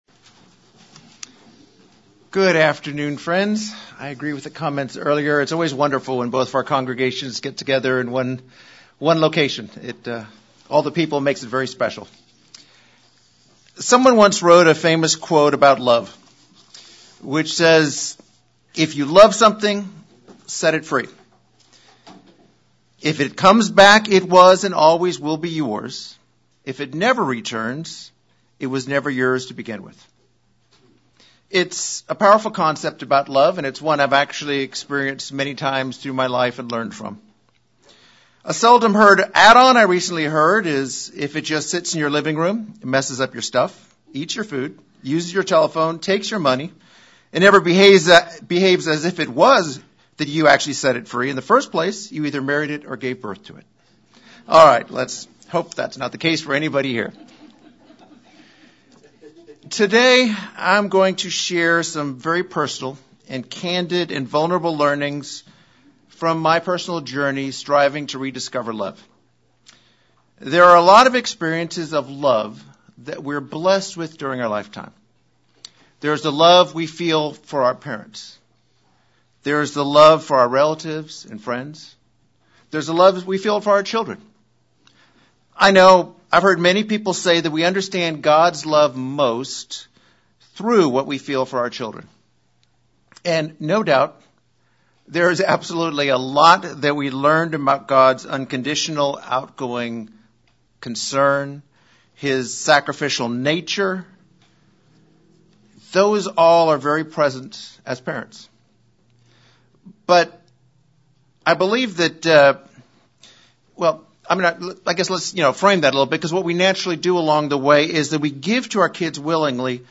Sermon looking at the ways we love God and our spouses and some of the struggles one endures when losing a spouse